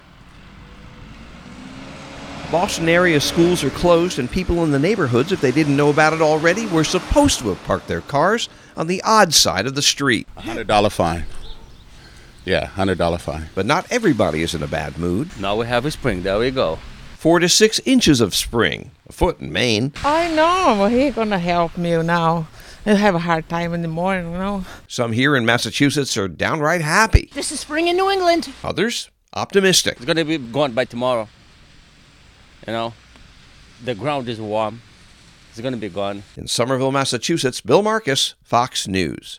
FROM OUTSIDE BOSTON: